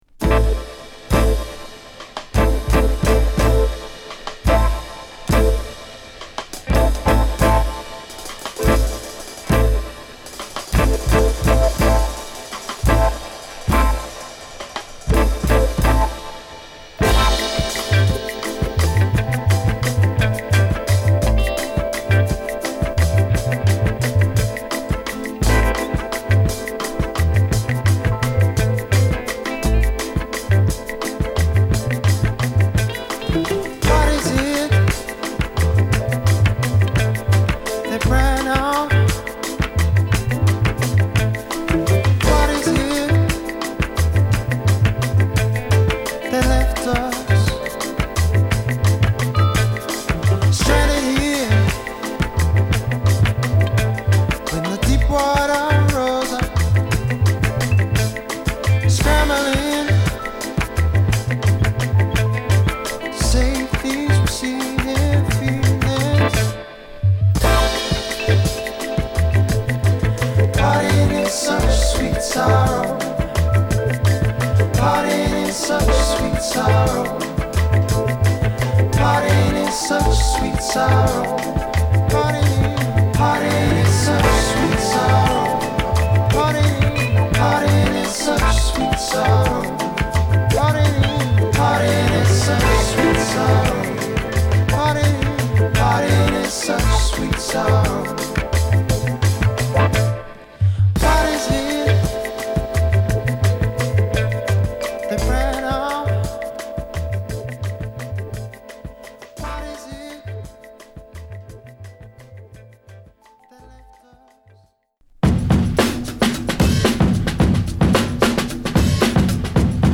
極太のドラムにドライブするベース／アフロヴァイブスのホーン等が絡む、ド渋なファンクトラック